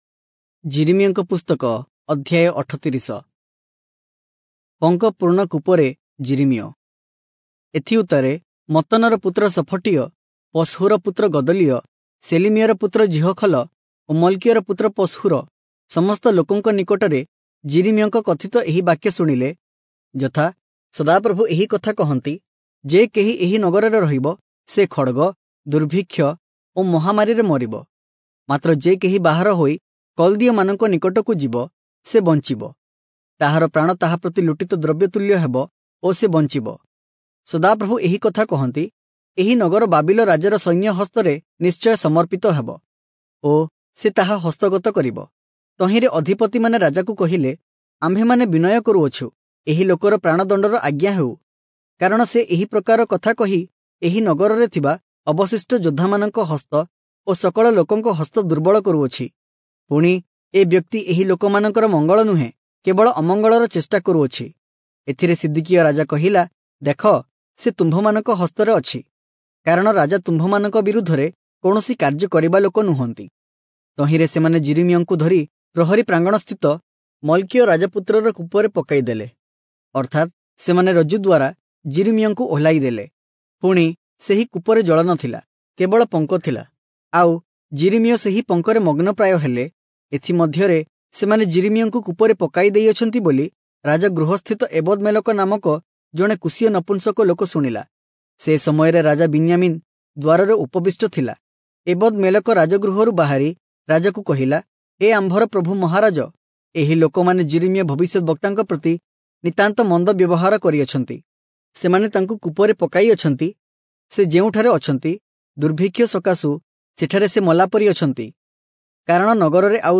Oriya Audio Bible - Jeremiah 8 in Irvor bible version